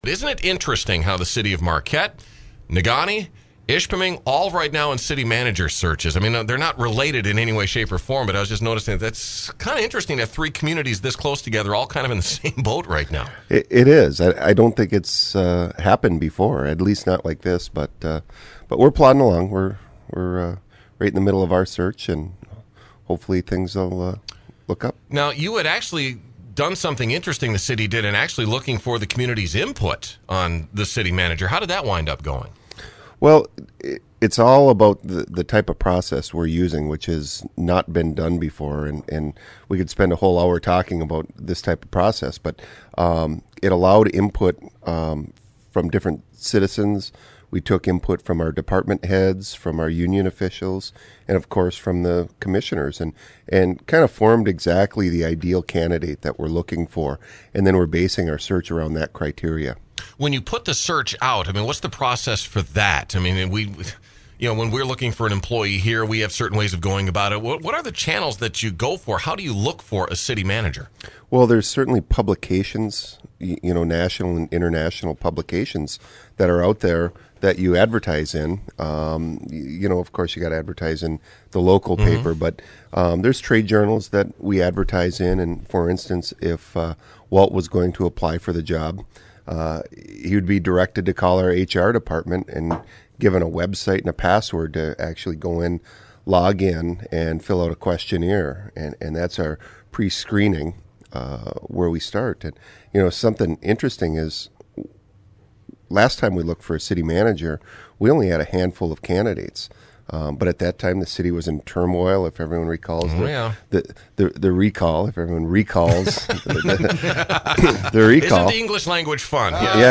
Marquette Mayor John Kivela stopped by the other day to spend a few minutes on the air explaining some of the things that are currently happening in Marquette. From the latest with the search for a new City Manager to road and planned construction projects, Mayor Kivela gave us the run down on what’s on the agenda for the city over the next few months.